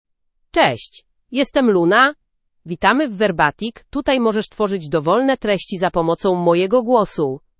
Luna — Female Polish (Poland) AI Voice | TTS, Voice Cloning & Video | Verbatik AI
LunaFemale Polish AI voice
Luna is a female AI voice for Polish (Poland).
Voice sample
Listen to Luna's female Polish voice.
Luna delivers clear pronunciation with authentic Poland Polish intonation, making your content sound professionally produced.